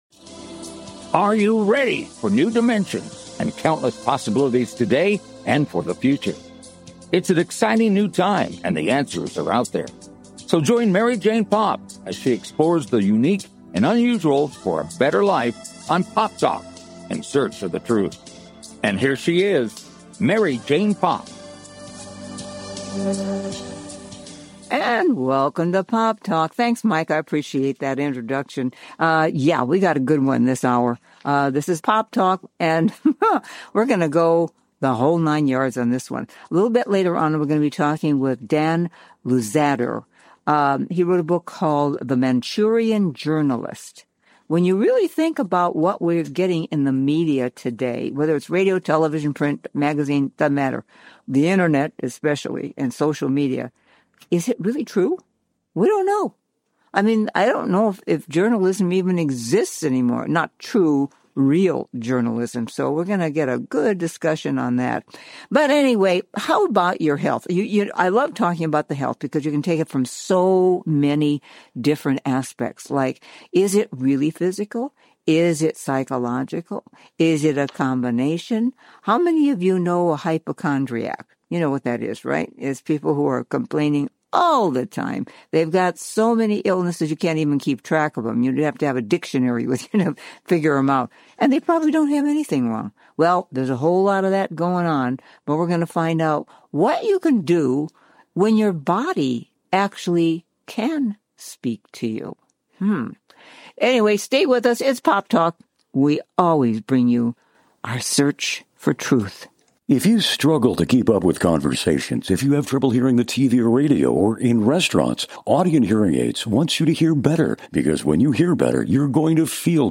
Talk Show Episode
A fast-paced Magazine-style Show dedicated to keeping you on the cutting edge of today's hot button issues. The show is high energy, upbeat and entertaining.